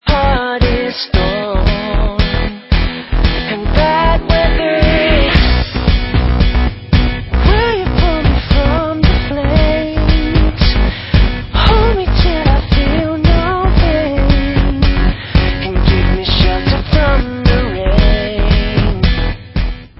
britští poprockeři